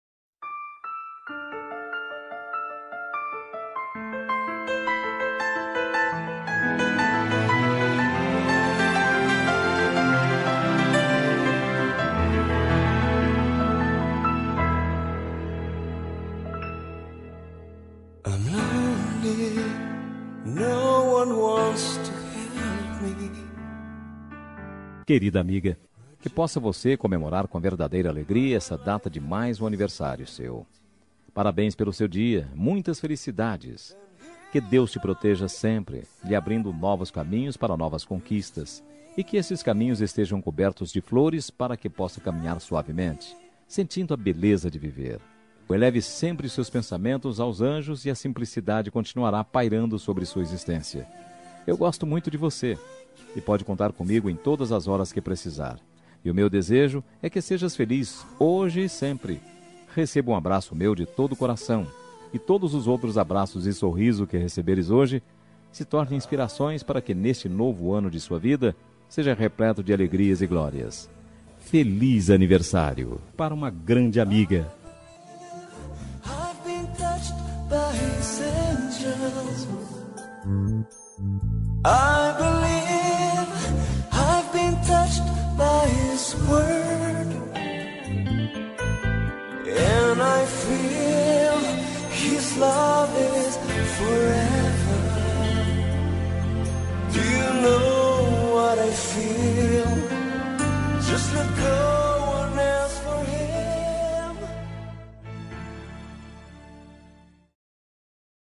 Aniversário de Amiga Gospel – Voz Masculina – Cód: 6022